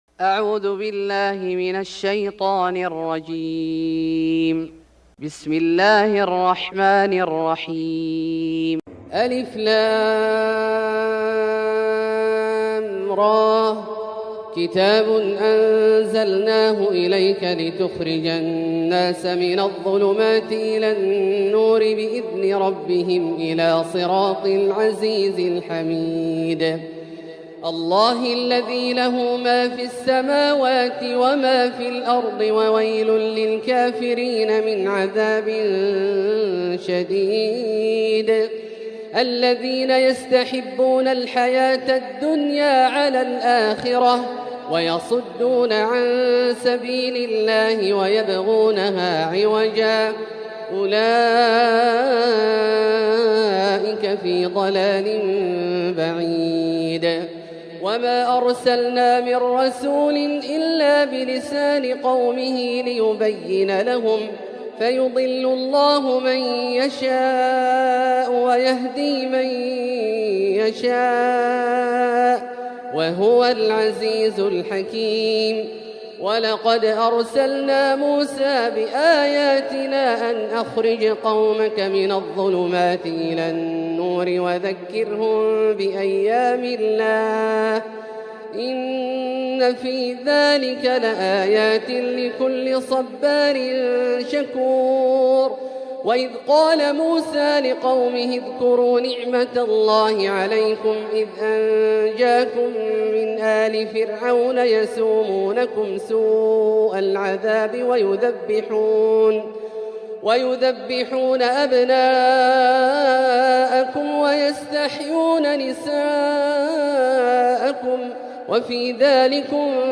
سورة إبراهيم Surat Ibrahim > مصحف الشيخ عبدالله الجهني من الحرم المكي > المصحف - تلاوات الحرمين